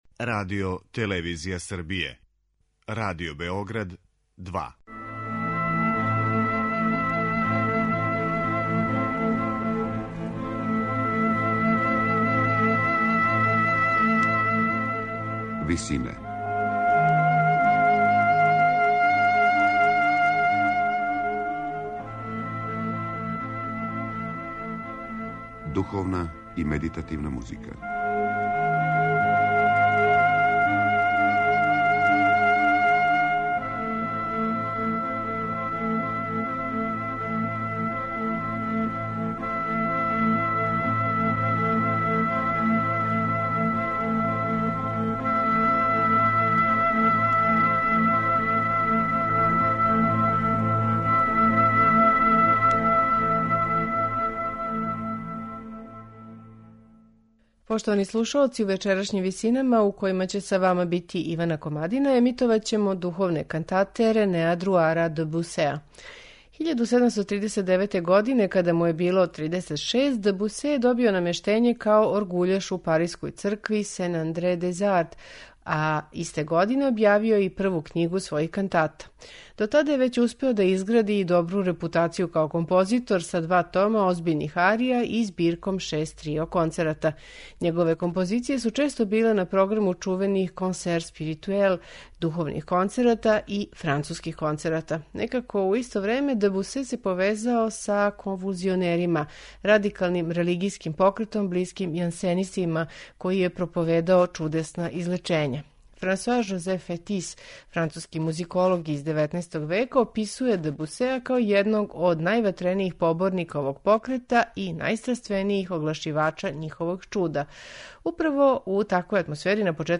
Емитујемо Дe Бусеове кантате
баритон
сопран
попречна флаута
обоа
виола да гамба
теорба
чембало